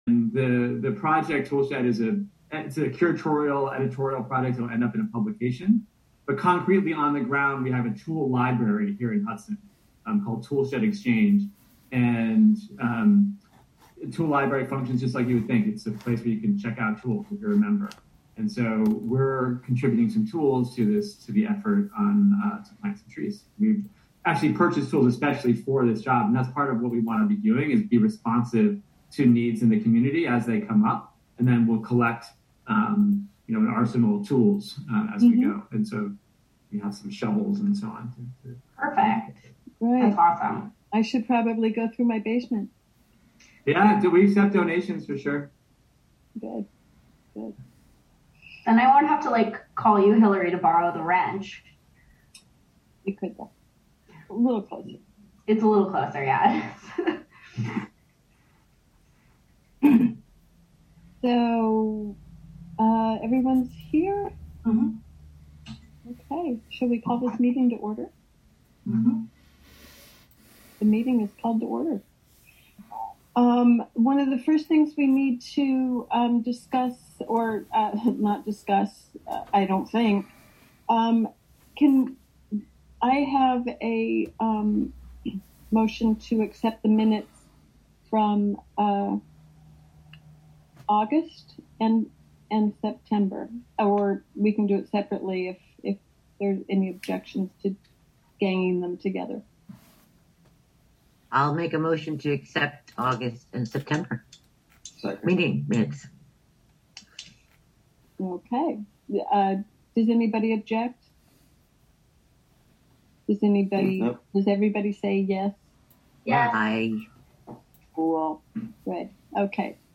Live from the City of Hudson: Hudson Conservation Advisory Council (Audio)